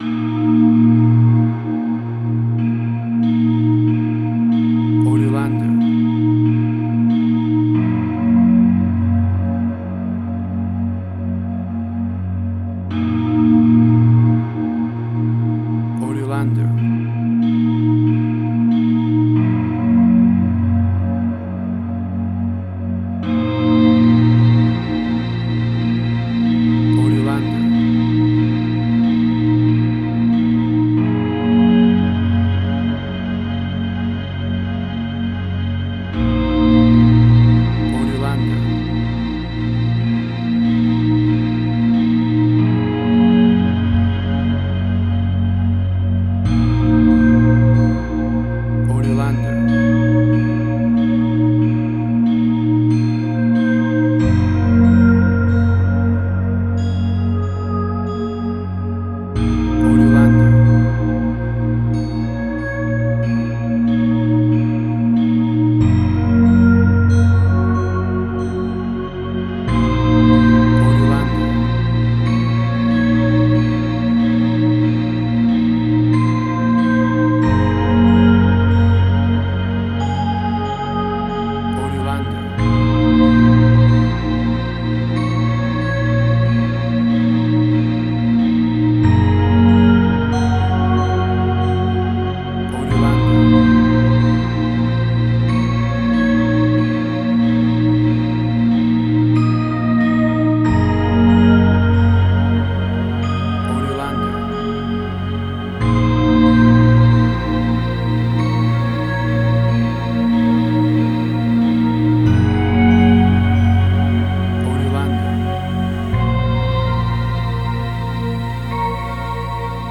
New Age
Tempo (BPM): 47